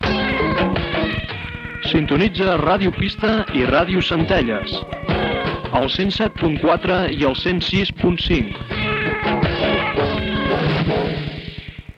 Identificació i freqüències de Ràdio Pista i Ràdio Centelles